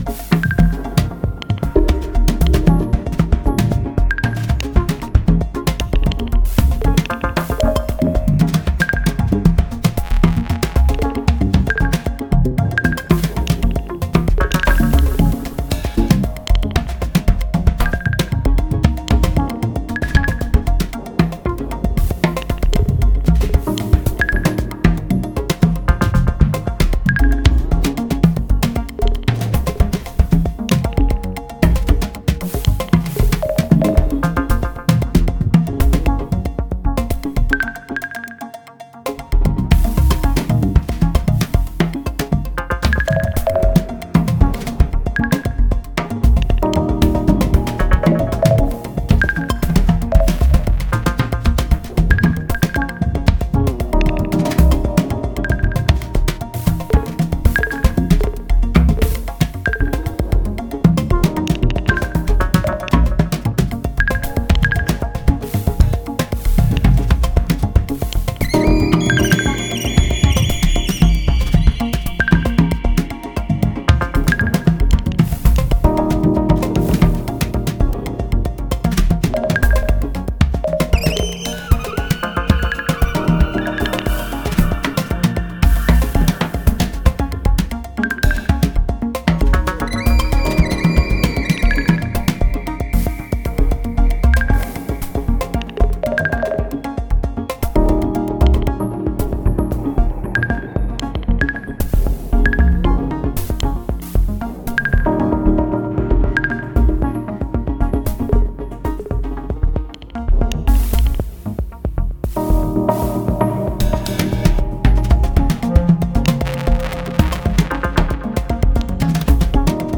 ポリリズミックかつ有機的なレイヤーにを描く